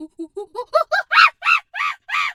pgs/Assets/Audio/Animal_Impersonations/monkey_2_chatter_scream_06.wav at master
monkey_2_chatter_scream_06.wav